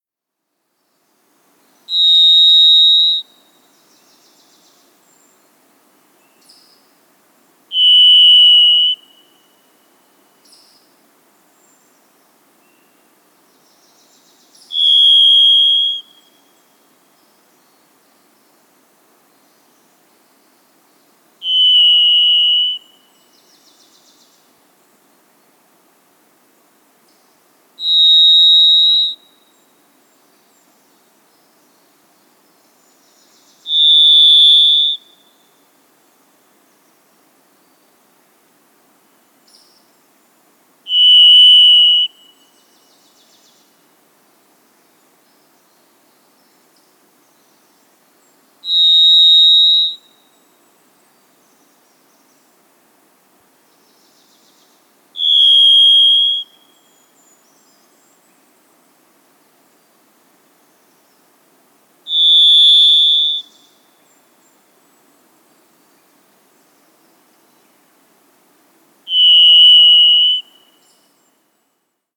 Varied Thrush
How they sound: Varied Thrushes sing a whistled, flutelike, sometimes burry tone on a single pitch.